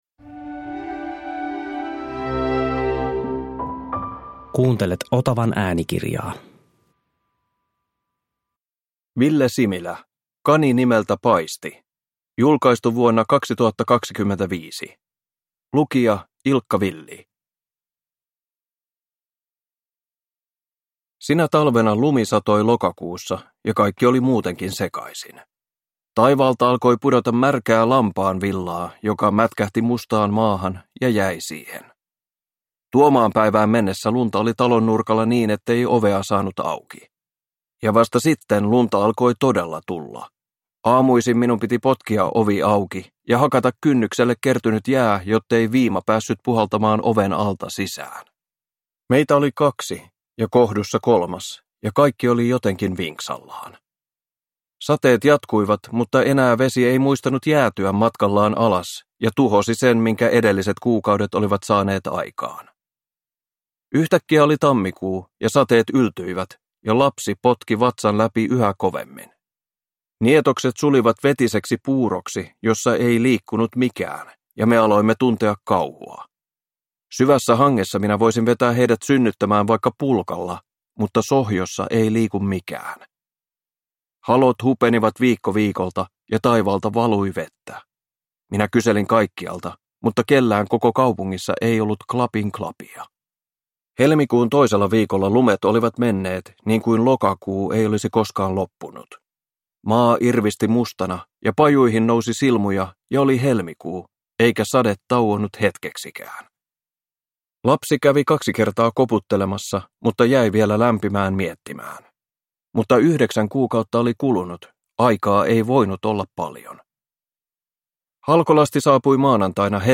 Kani nimeltä Paisti (ljudbok) av Ville Similä